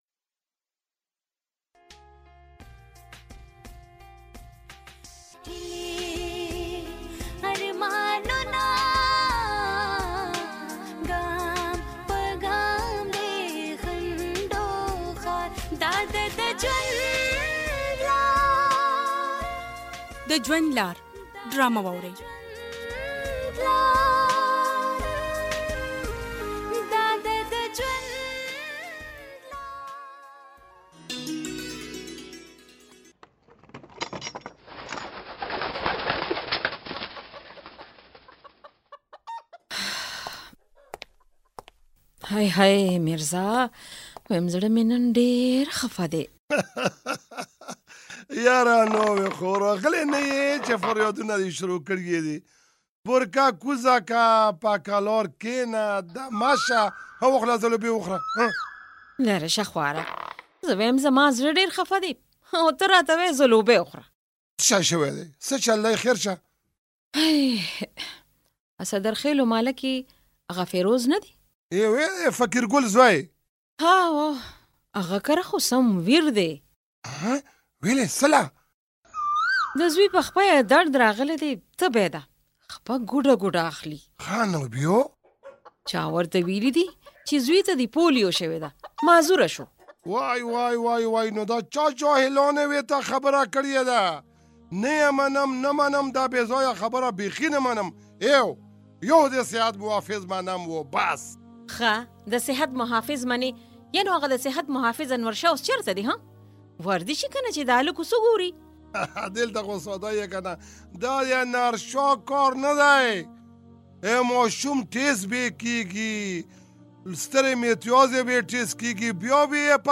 ډرامه: د ژوند لار(ووم ټوک)